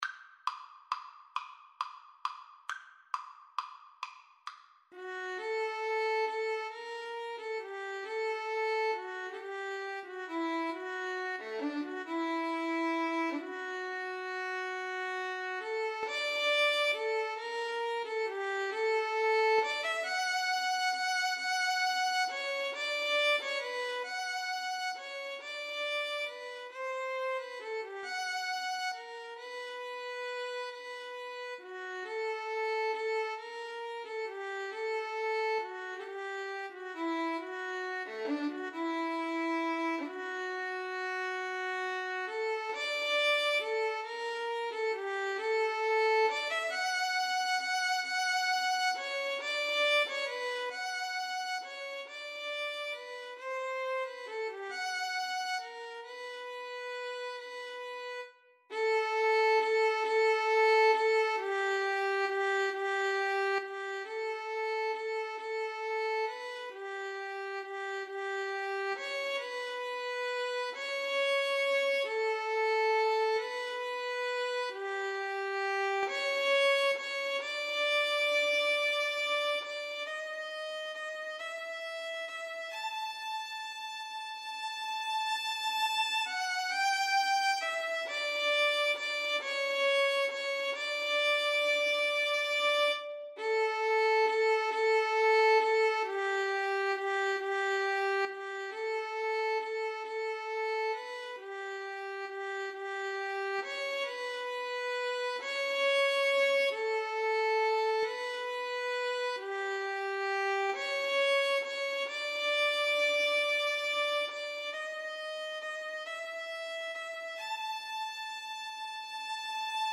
Free Sheet music for Violin Duet
Violin 1Violin 2
6/8 (View more 6/8 Music)
D major (Sounding Pitch) (View more D major Music for Violin Duet )
Andantino .=c.45 (View more music marked Andantino)
Classical (View more Classical Violin Duet Music)